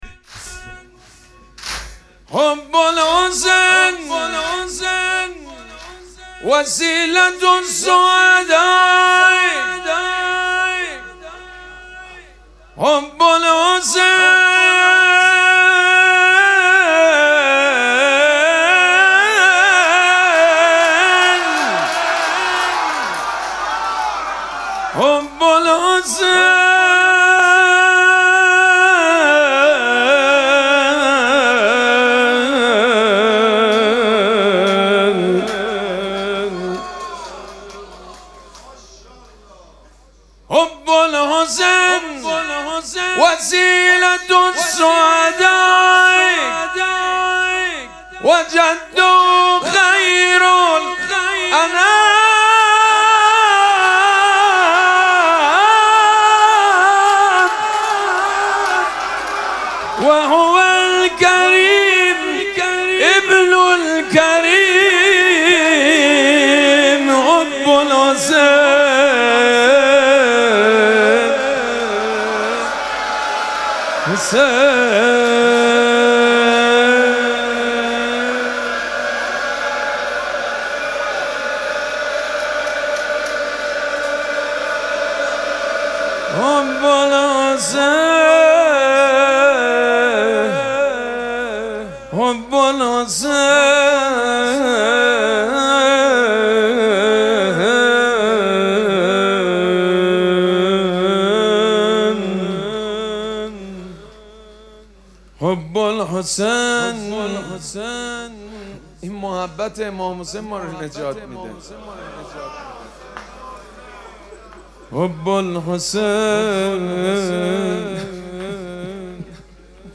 مراسم مناجات شب بیست و سوم ماه مبارک رمضان
حسینیه ریحانه الحسین سلام الله علیها
نغمه خوانی
حاج سید مجید بنی فاطمه